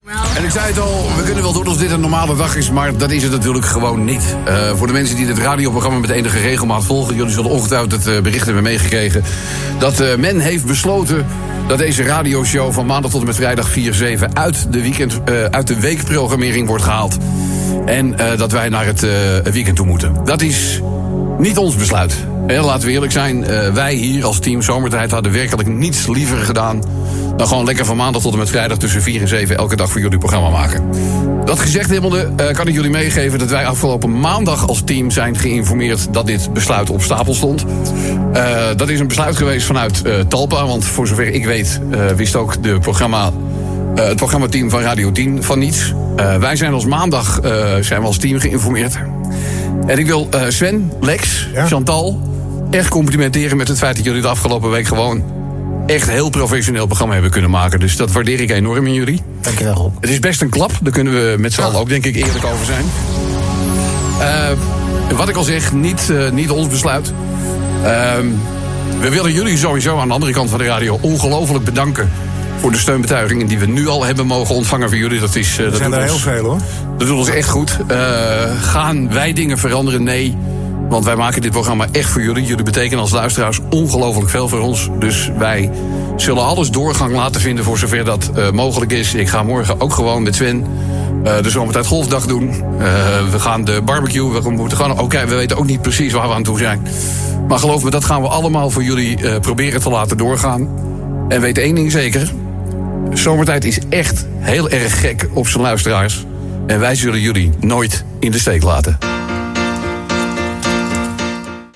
Rob van Someren zegt overvallen te zijn door het besluit dat zijn programma ‘Somertijd’ uit de middag verdwijnt. “Wij zijn afgelopen maandag als team geïnformeerd dat dit besluit op stapel stond”, aldus de deejay aan het begin van zijn programma.
“Somertijd is echt gek op zijn luisteraars en wij zullen jullie nooit in de steek laten”, besloot een emotionele Van Someren.